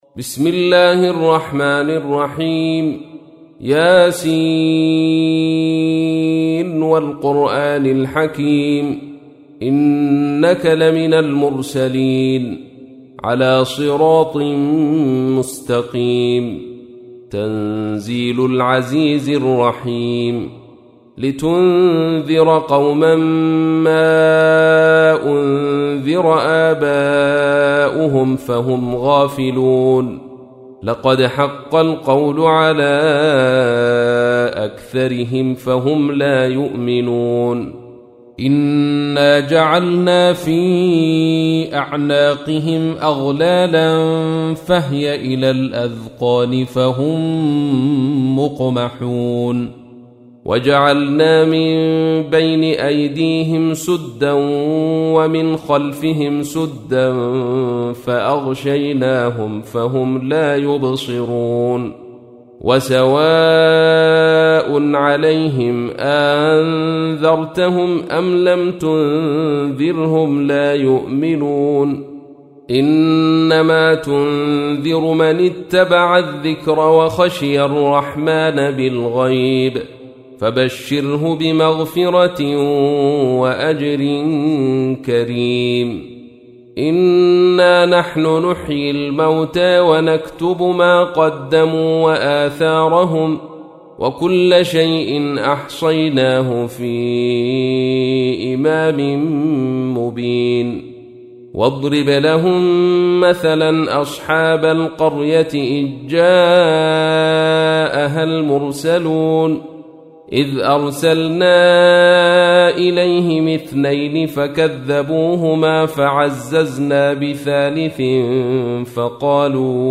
تحميل : 36. سورة يس / القارئ عبد الرشيد صوفي / القرآن الكريم / موقع يا حسين